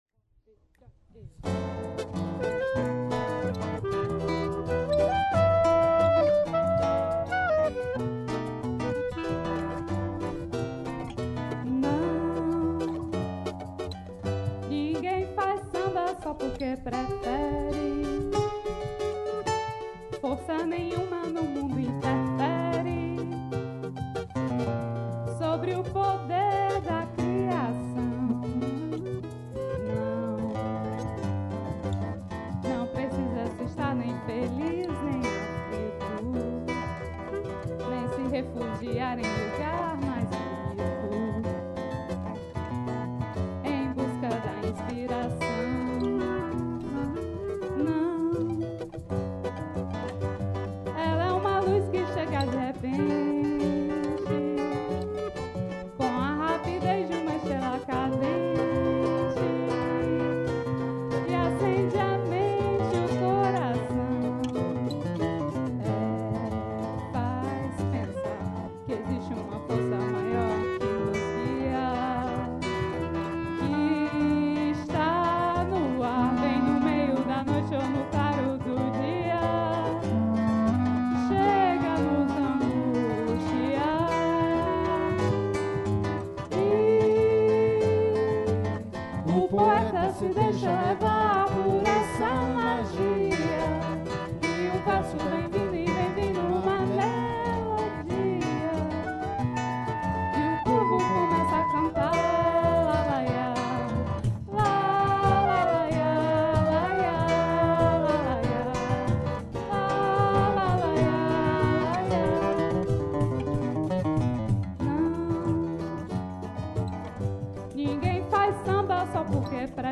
Joué pour la fête de la Musique 2024 au CE TAS